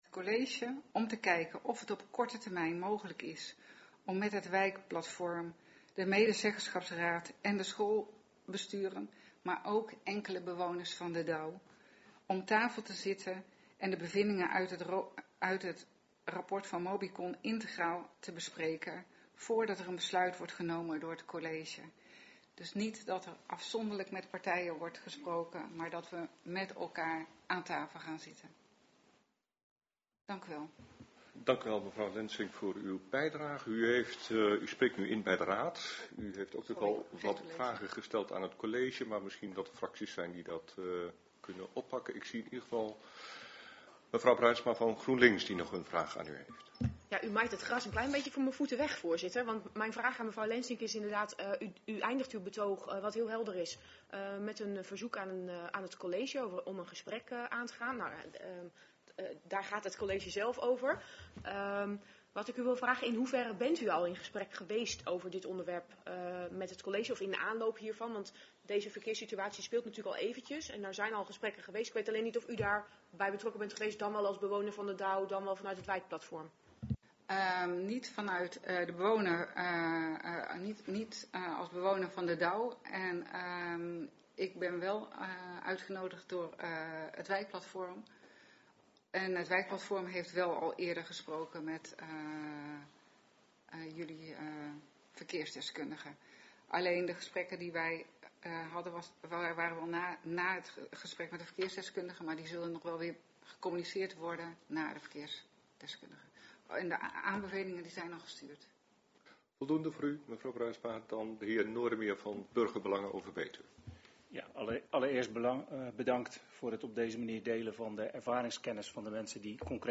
Helaas is er door technische problemen geen geluid bij dit raadspreekuur.